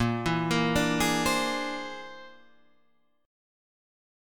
Bb9b5 chord